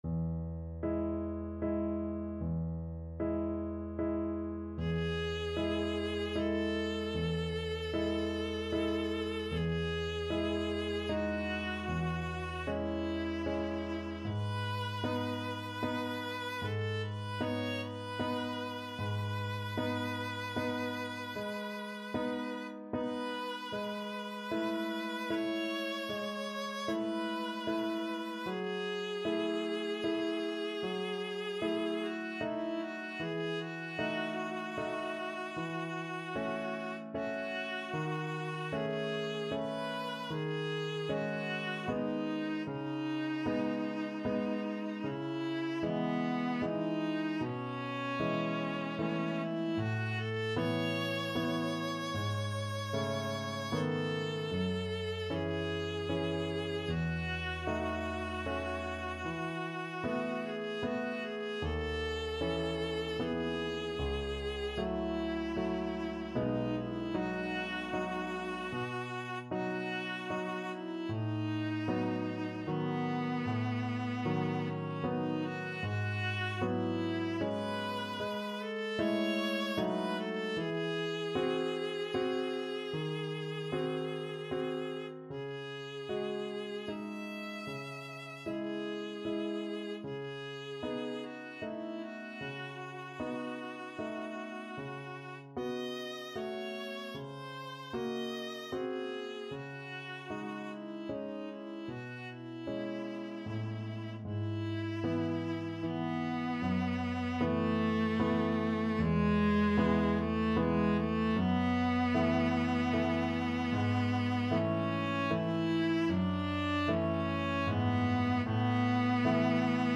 3/4 (View more 3/4 Music)
Adagio assai =76
Ab4-F#6
Classical (View more Classical Viola Music)